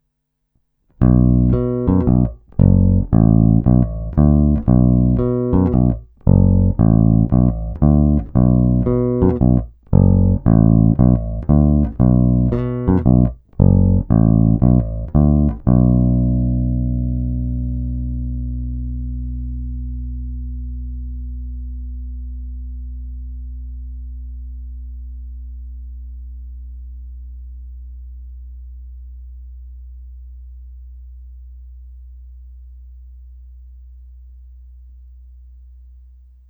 Hlazenky base sluší, zvuk je pěkně tučný, pevný, má ty správné středy tmelící kapelní zvuk.
Není-li uvedeno jinak, následující nahrávky jsou provedeny rovnou do zvukové karty, jen normalizovány, jinak ponechány bez úprav.
Oba snímače